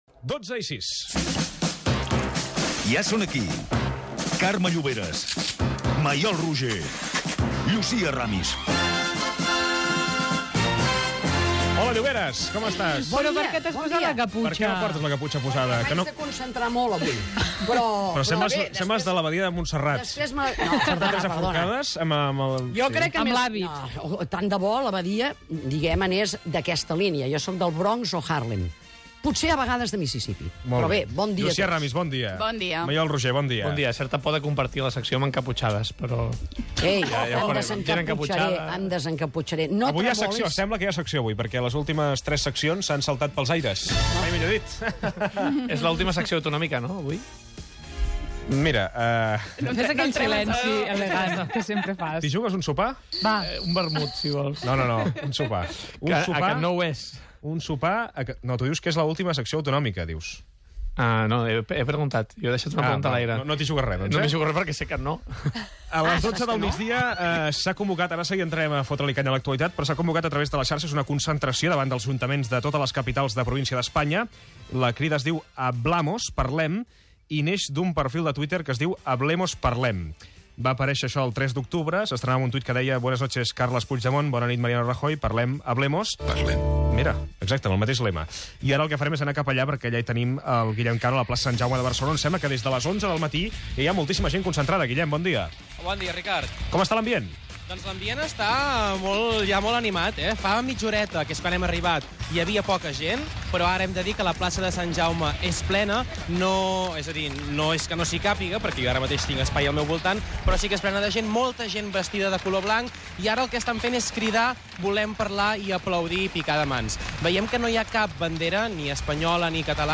0655cd3a107d97dc75c088fa170cbbb5ee7e15bf.mp3 Títol Catalunya Ràdio Emissora Catalunya Ràdio Cadena Catalunya Ràdio Titularitat Pública nacional Nom programa El suplement Descripció Hora, secció "Fot-li canya". Connexió amb la Plaça Sant Jaume on es fa una concentració de "Parlem-Hablemos". Connexió amb Madrid on es fan dues manifestacions. Comentaris sobre el paper de la premsa espanyola en la informació del Referèndum d'Autodeterminació de Catalunya celebrat el dia 1 d'octubre del 2107.